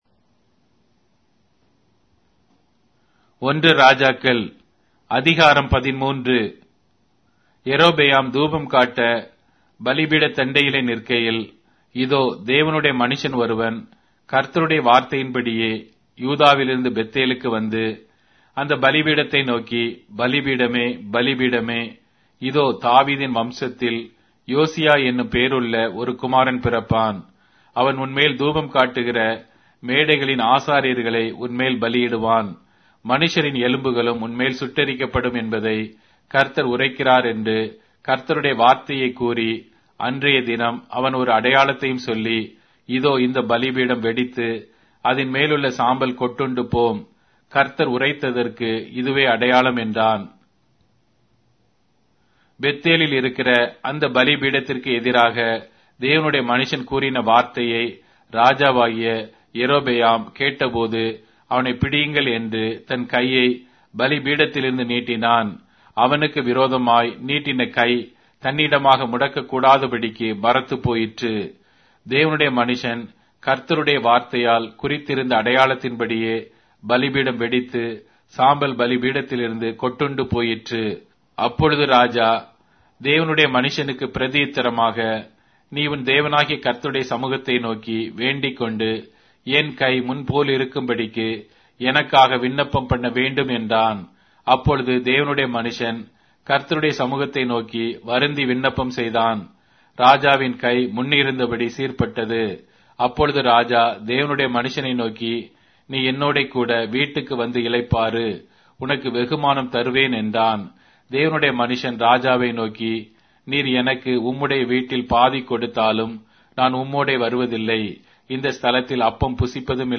Tamil Audio Bible - 1-Kings 16 in Irvbn bible version